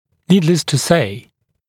[‘niːdləs tə seɪ][‘ни:длэс ту сэй]нет необходимости говорить; само собой разумеется (часто используется как вводная фраза)